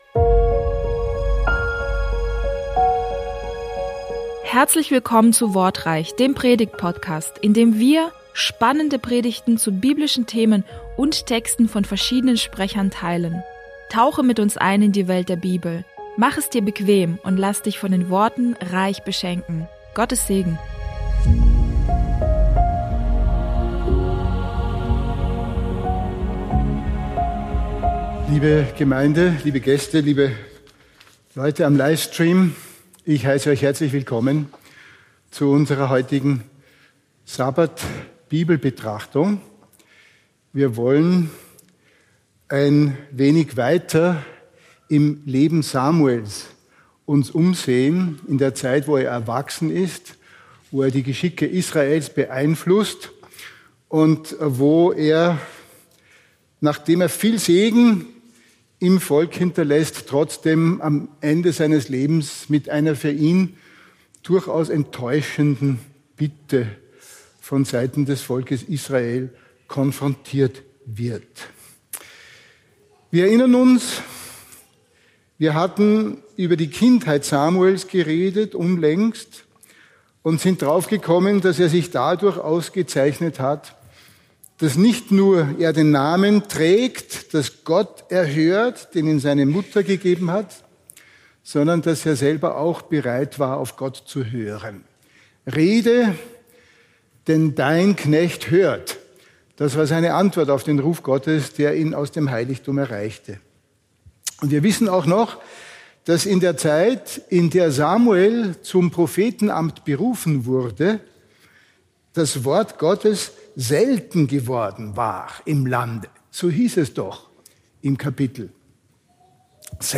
Hier hörst du Predigten aus Bogenhofen von unterschiedlichen Predigern, die dich näher zu Gott bringen und deinen Glauben festigen.